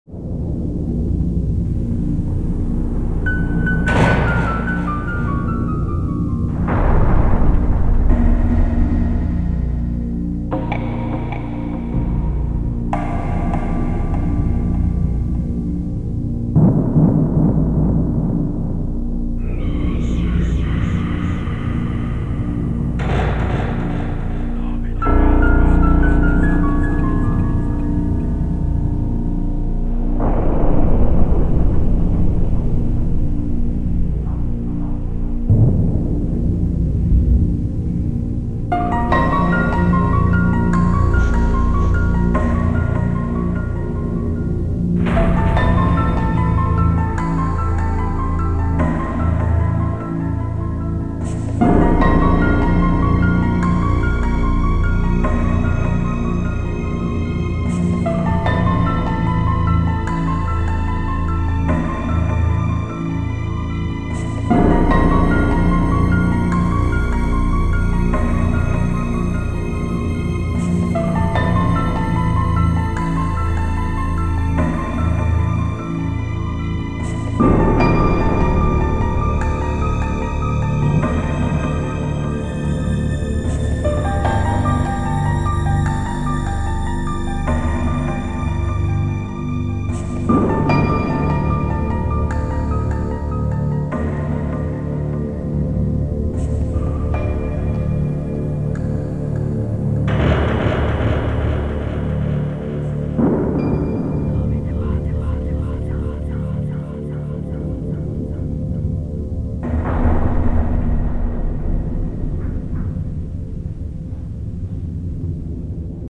Synthpop